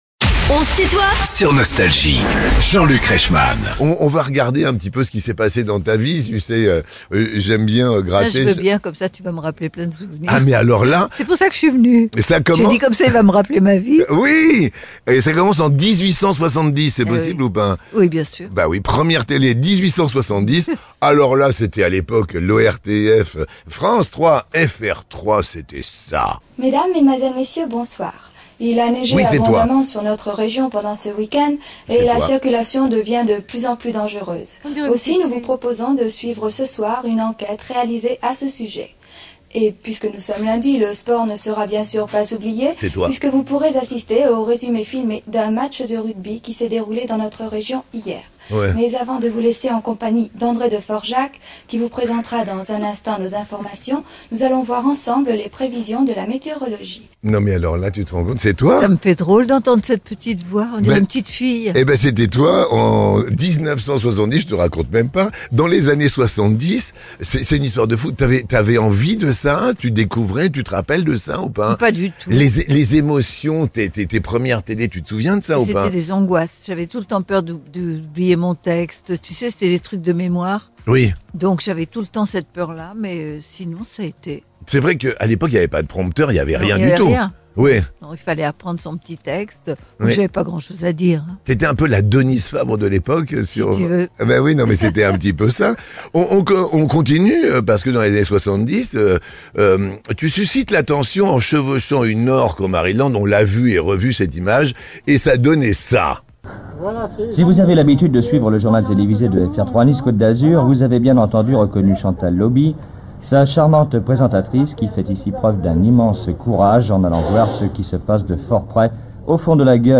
Chantal Lauby est l'invitée de "On se tutoie ?..." avec Jean-Luc Reichmann